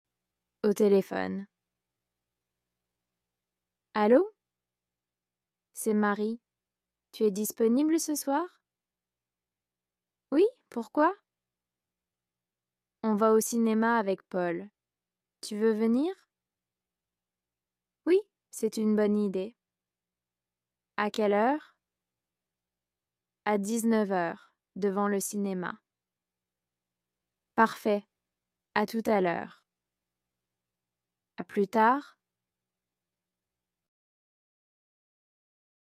Dialogue FLE – Au téléphone (niveau A2)